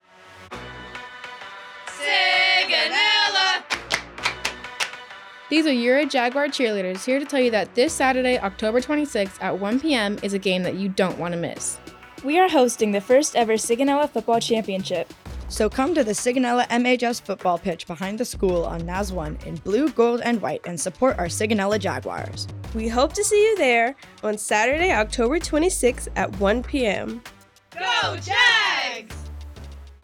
NAVAL AIR STATION SIGONELLA, Italy (Oct. 22, 2024) Radio spot promotes the Sigonella Middle and Highschool (MHS) Jaguar Football Championship game.